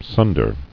[sun·der]